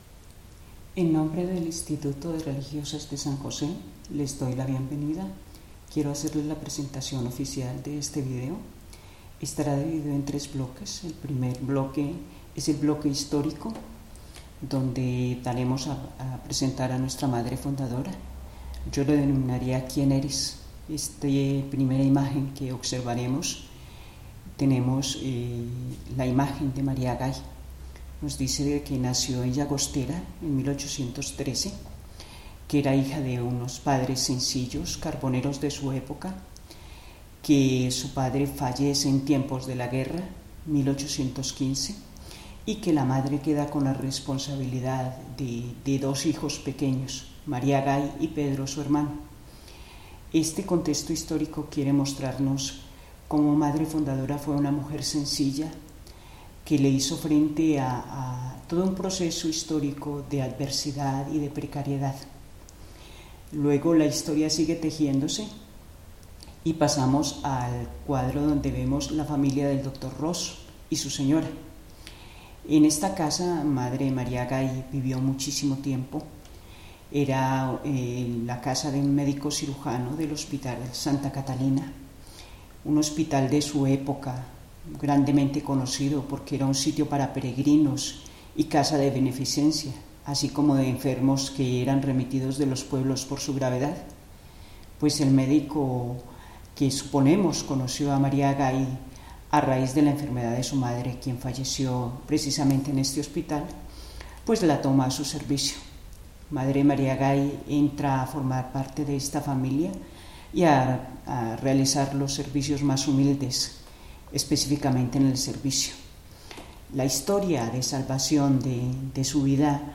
Género: Pop.